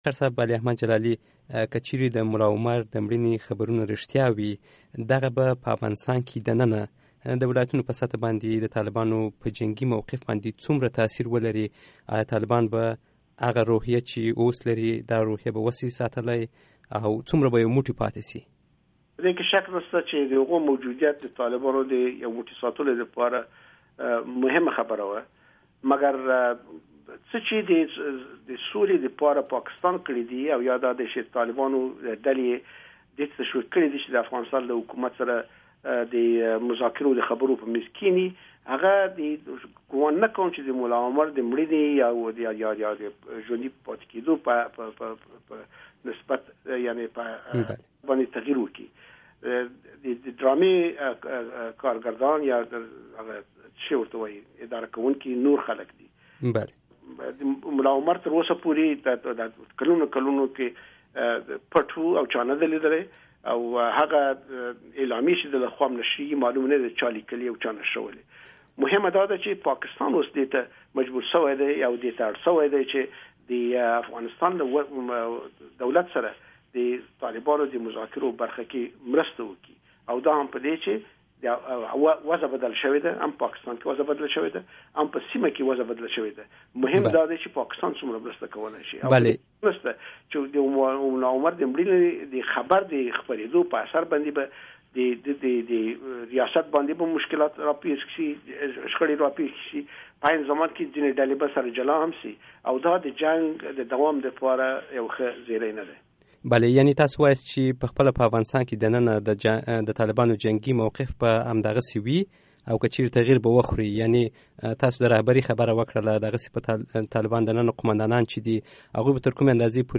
Interview with Jalali